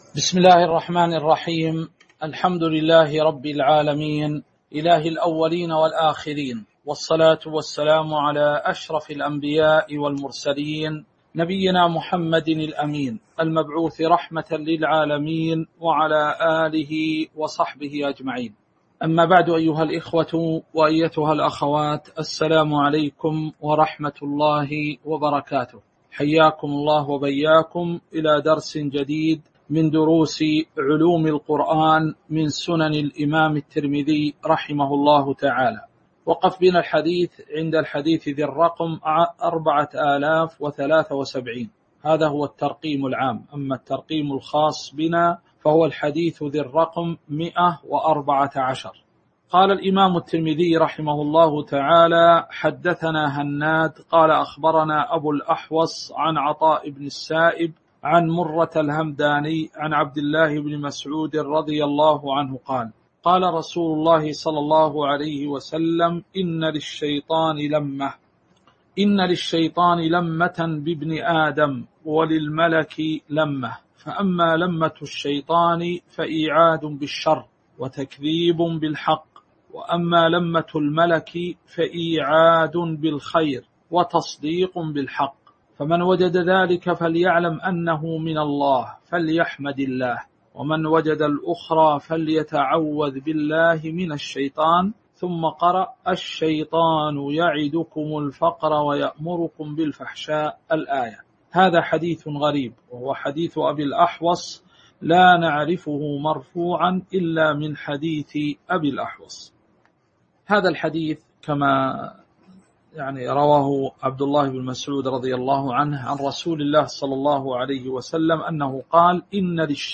تاريخ النشر ٢٦ صفر ١٤٤٣ هـ المكان: المسجد النبوي الشيخ